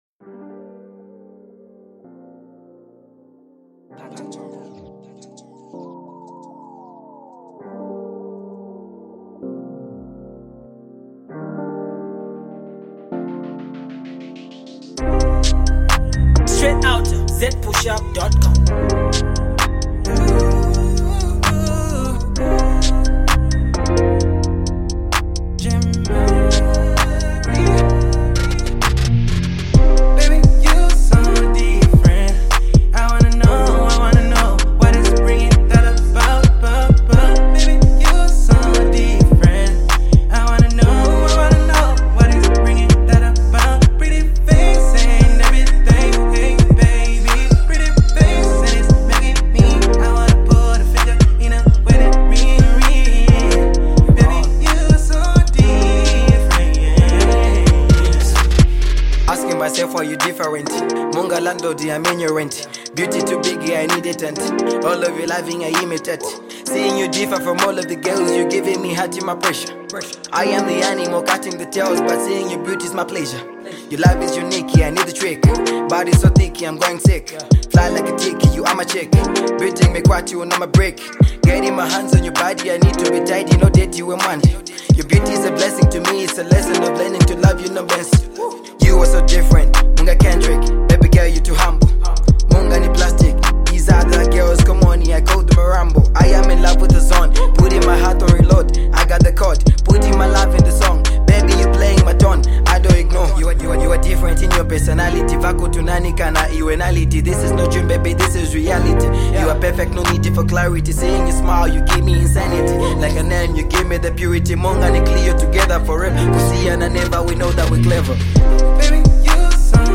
A Top notch RnB joint